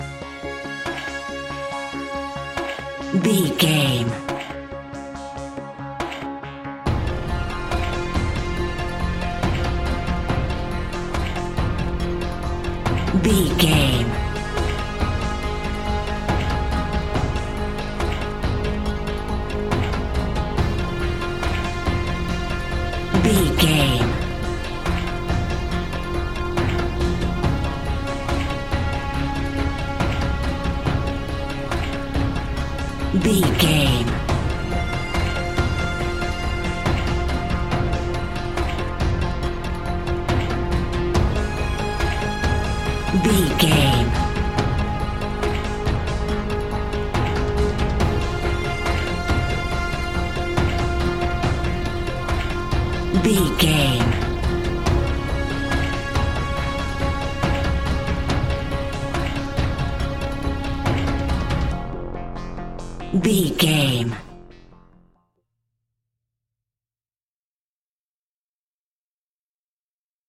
In-crescendo
Thriller
Aeolian/Minor
scary
ominous
dark
haunting
eerie
horror music
Horror Pads
Horror Synths